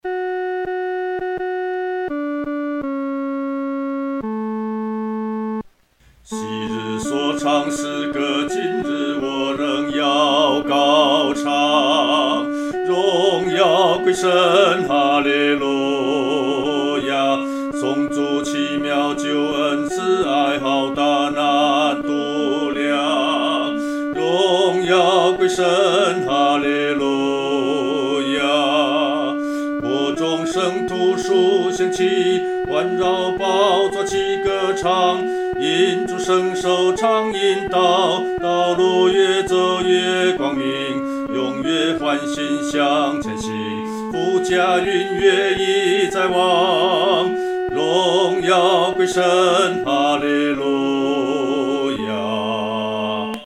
独唱（第二声）
这首赞美诗的曲调欢快，有进行曲的风味。
荣耀归神-独唱（第二声）.mp3